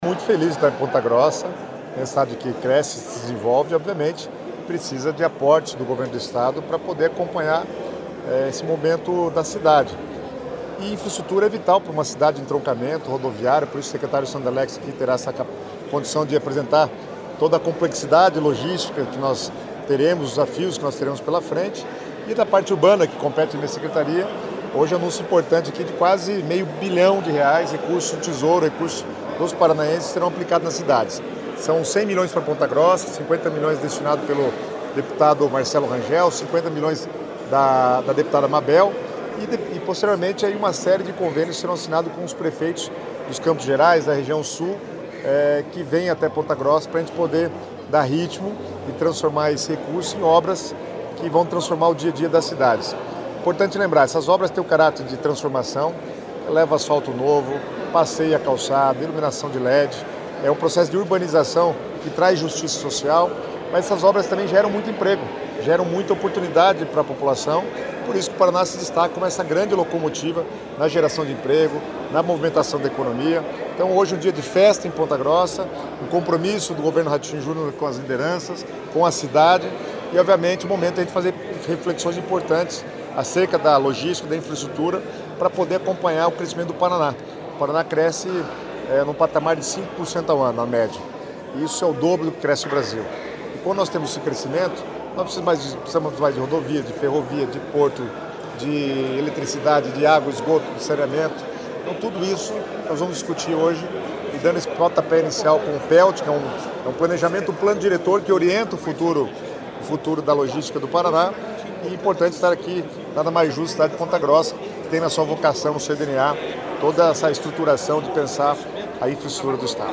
Sonora do secretário das Cidades, Guto Silva, sobre o Plano Estadual de Logística e Transportes